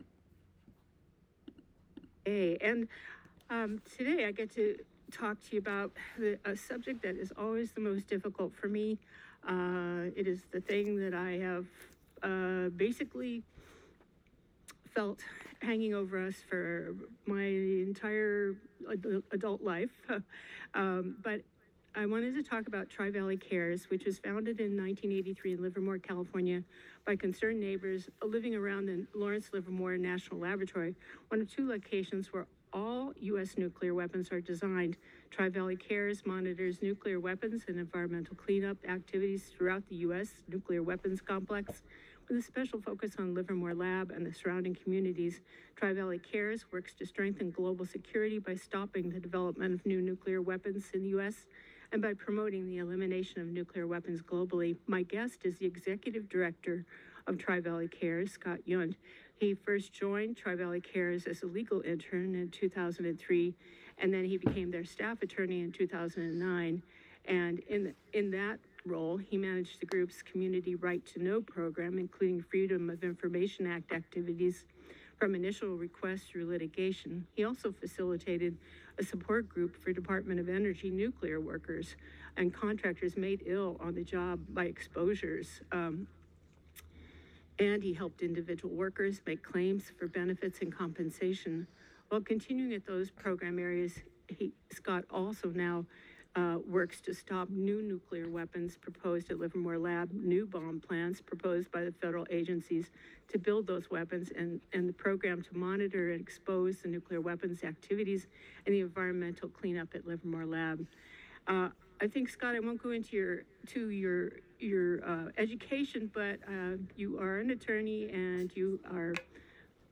Listen to the radio interview here Audio Player CLICK HERE TO VIEW ALL POSTS >
Peace-Social-Justice-21326-radio-interview.m4a